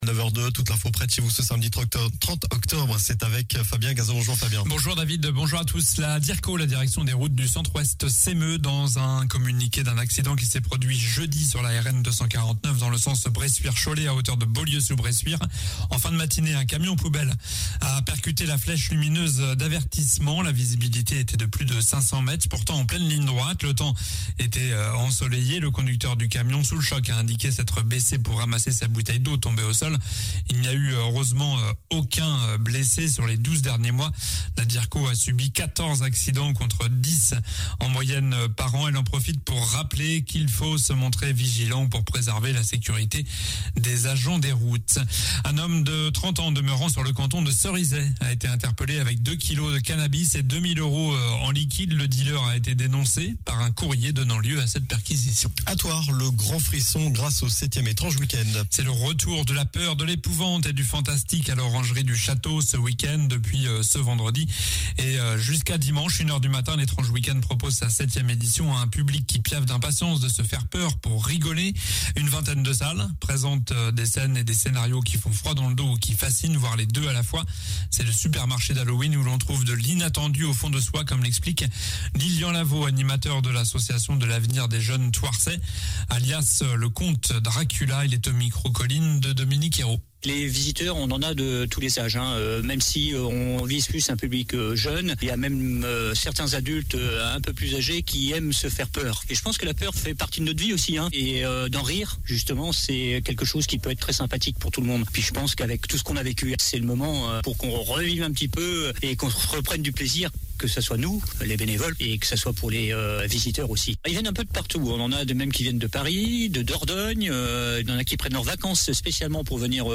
Journal du samedi 30 octobre (matin)